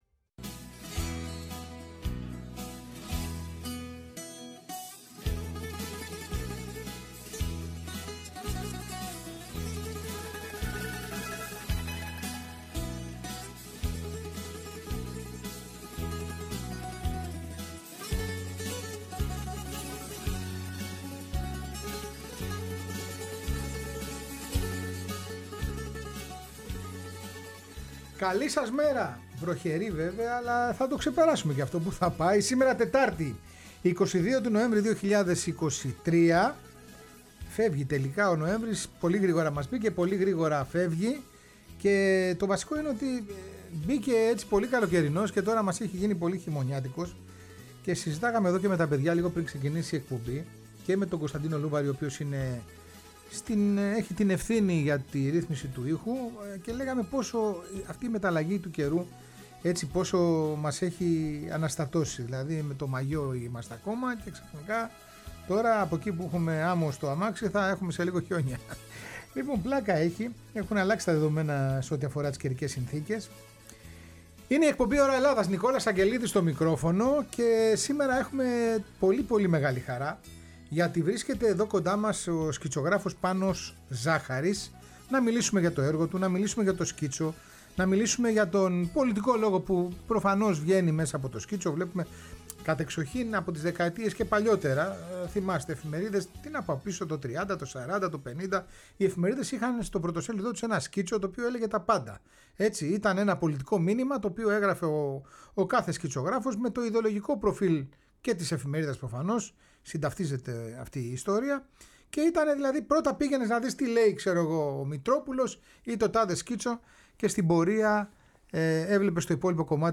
Μια συνέντευξη, ξεχωριστή, ανθρώπινη, με έντονο συναίσθημα και αγωνίες για ένα ανθρώπινο σήμερα και αύριο… Η ΦΩΝΗ ΤΗΣ ΕΛΛΑΔΑΣ